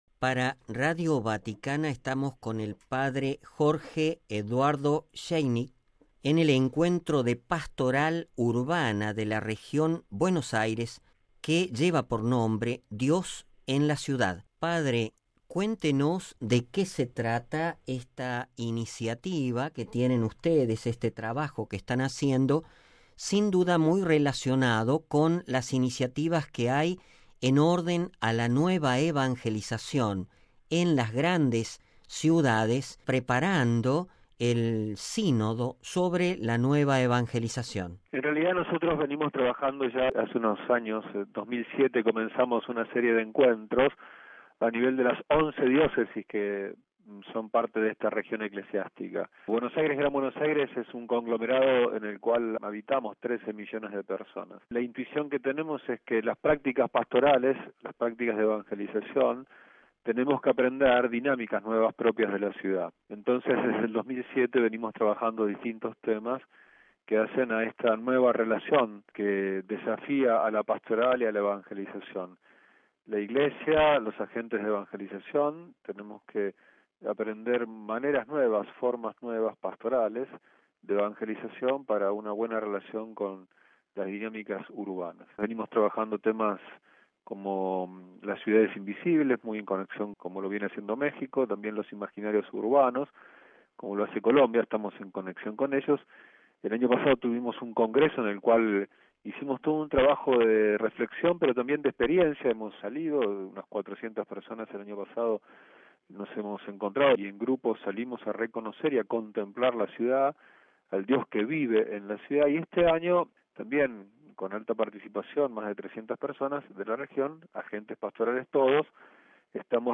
A continuación la entrevista completa (Audio) RealAudio